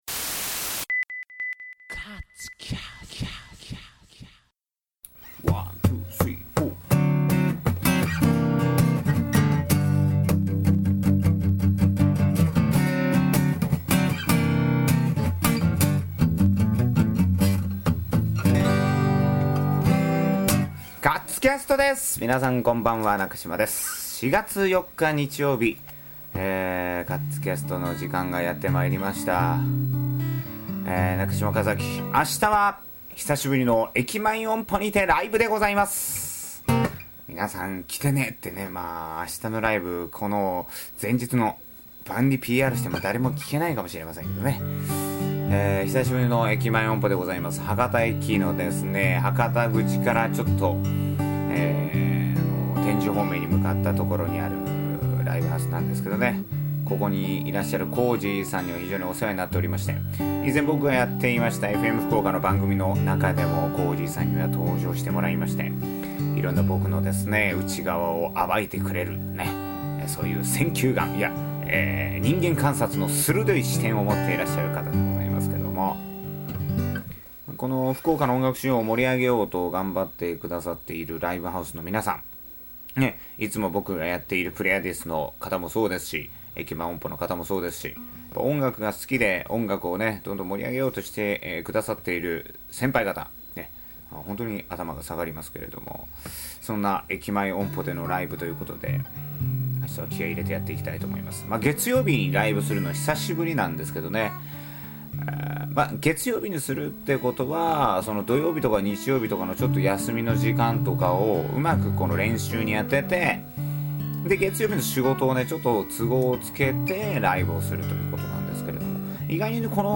ローテンションでお届けする日曜夜のｋａｚｃａｓｔ！！